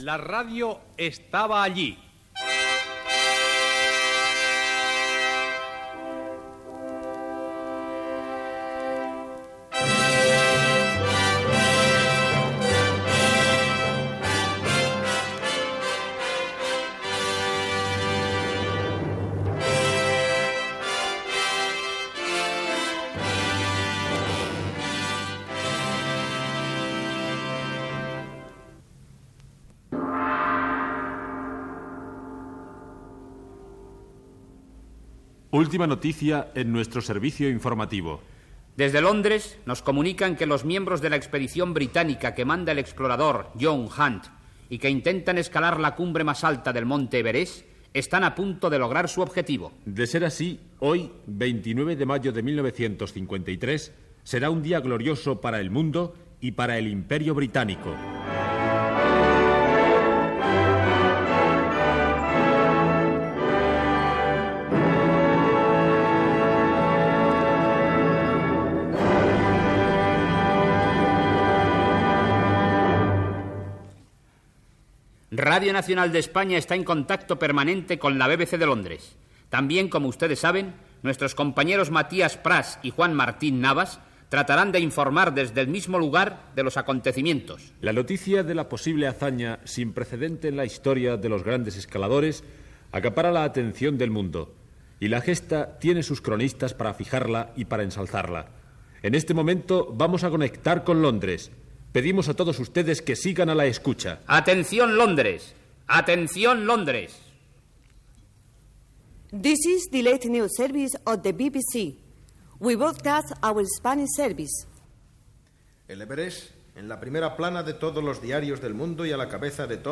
Careta del programa i fragment de l'espai dedicat a la conquesta del cim de l'Everest el 29 de maig de 1953. Gènere radiofònic Ficció